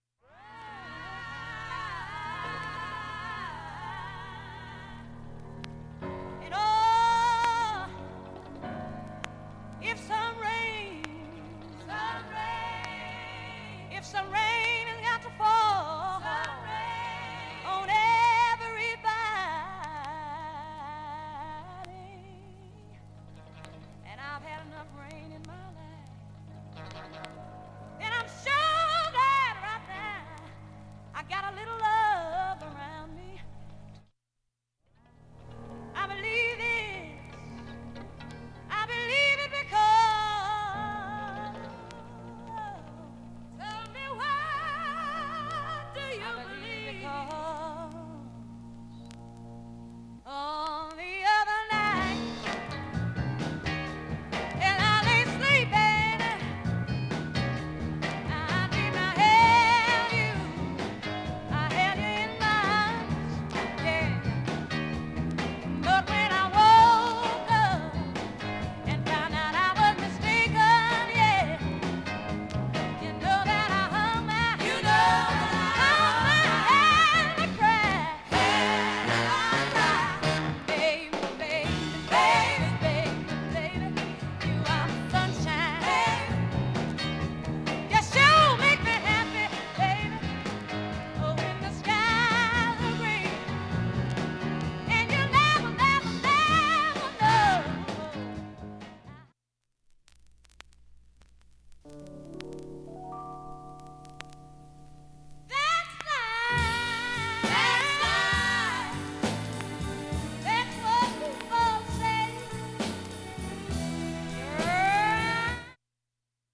音質良好全曲試聴済み。
A-2序盤にかすかなプツが14回出ます。
B-1始めにかすかなプツが７回出ます。